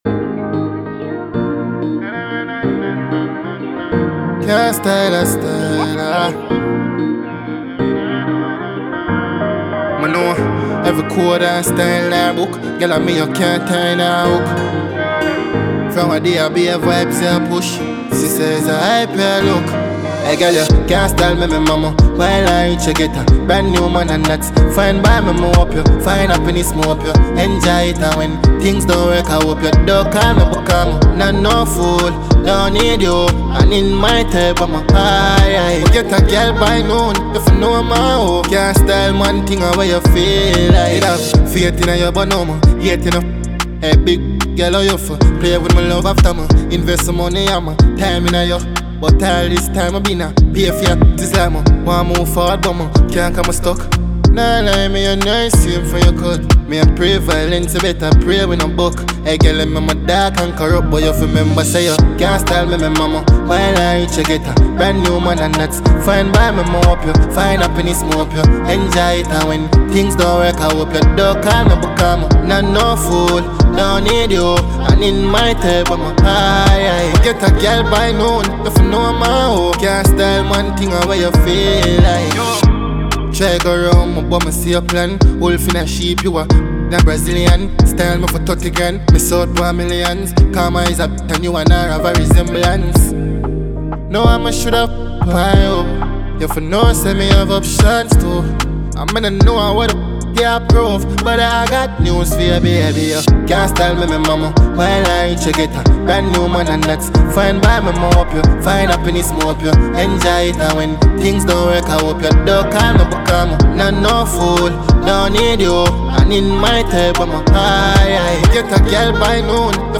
Genre: African.